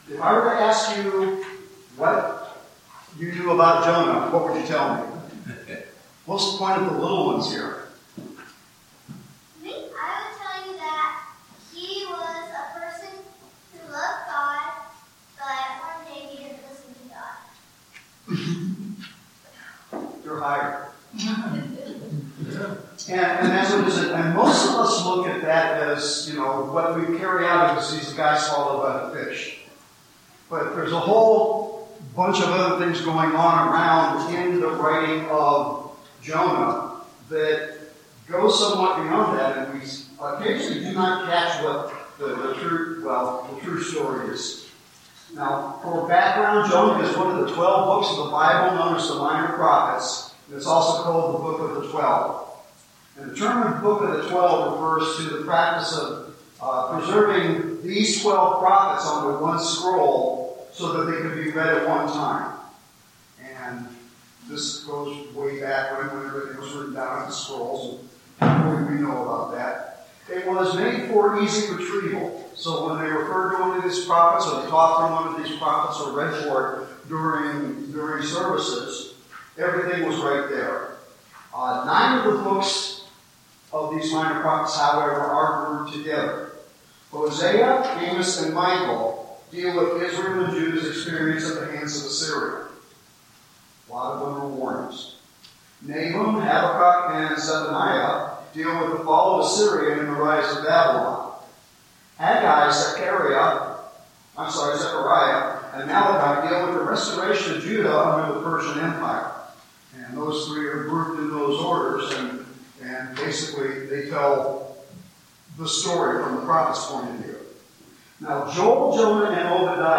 This is the start of our Sunday Evening study through Jonah. Due to technical difficulties, the audio is not the best.